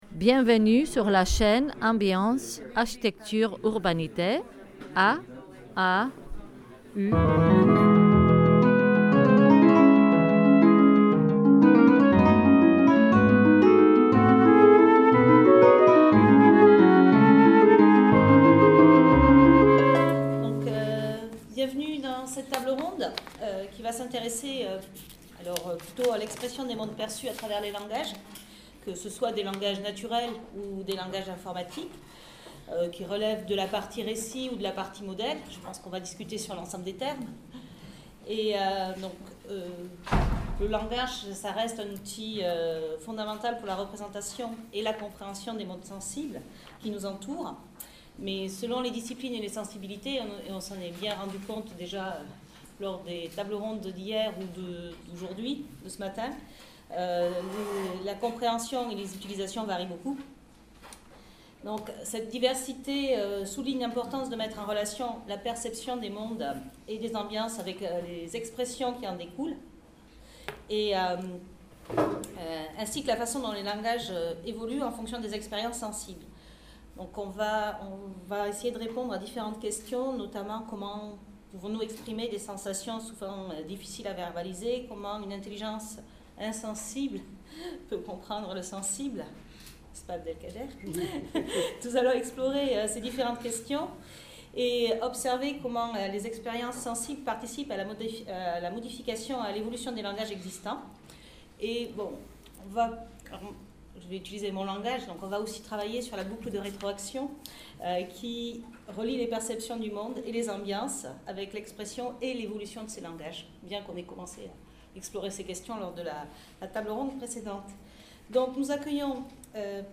Table ronde 2 : Exprimer par les langages : Modélisations et récits | Canal U